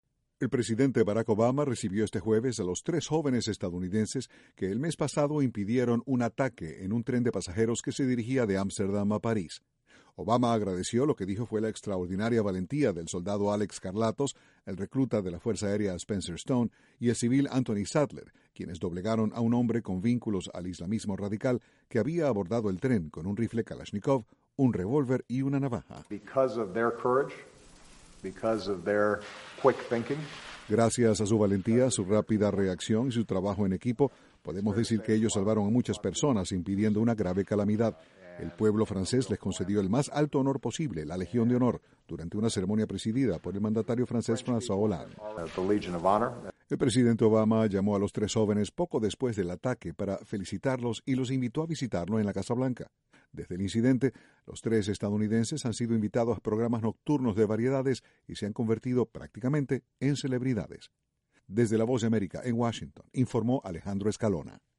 En la Casa Blanca, el presidente Barack Obama recibió a tres jóvenes que recibieron la Legión de Honor de manos del presidente Francois Hollande. Desde la Voz de América, Washington, informa